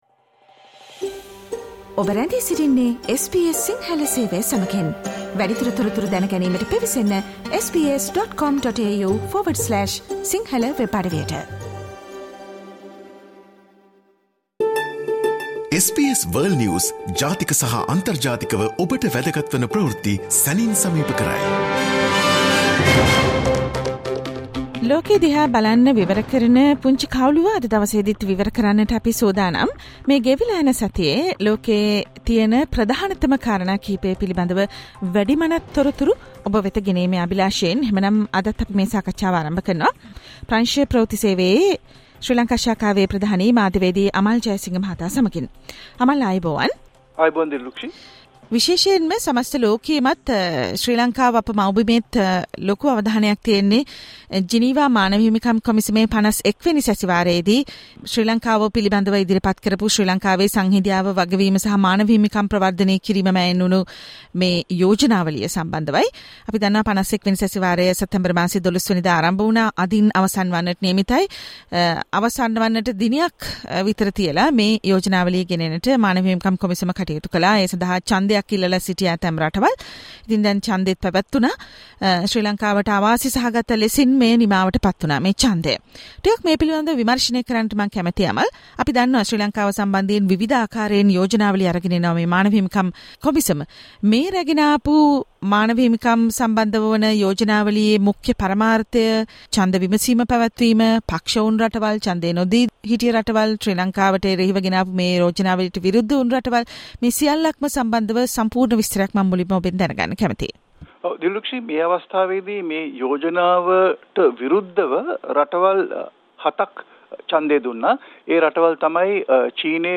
listen to the SBS Sinhala Radio weekly world News wrap every Friday